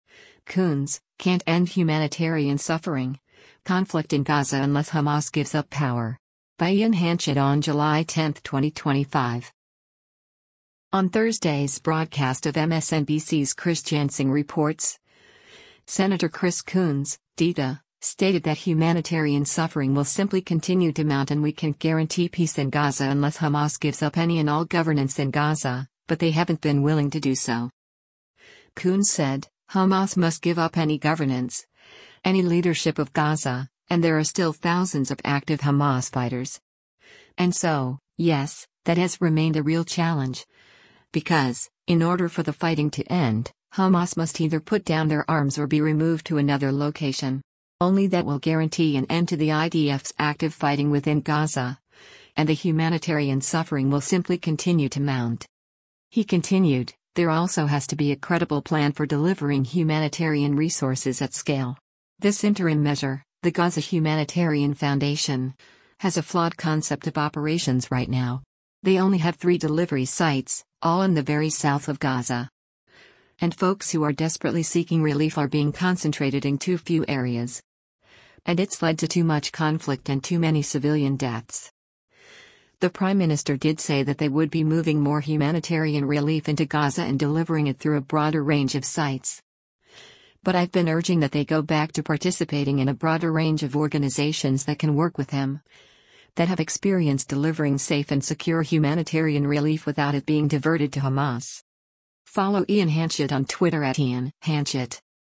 On Thursday’s broadcast of MSNBC’s “Chris Jansing Reports,” Sen. Chris Coons (D-DE) stated that “humanitarian suffering will simply continue to mount” and we can’t guarantee peace in Gaza unless Hamas gives up any and all governance in Gaza, but they haven’t been willing to do so.